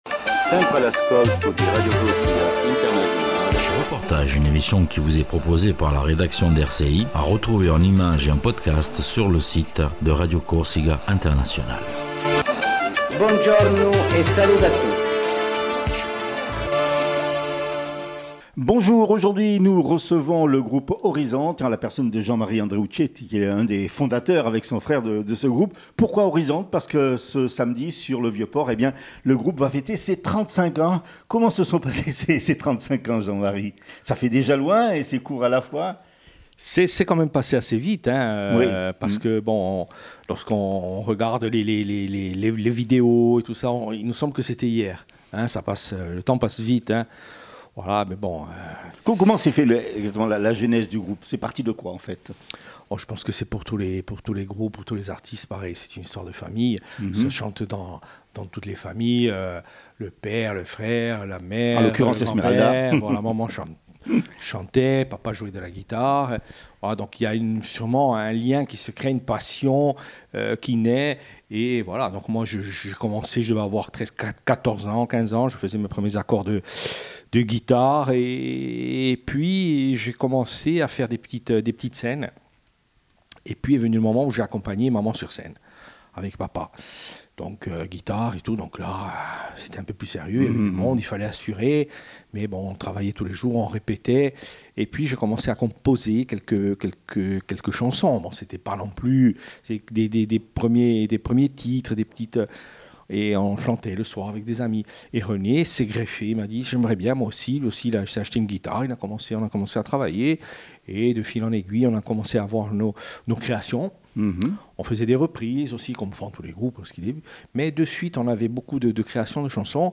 REPORTAGE SUR LE GROUPE ORIZONTE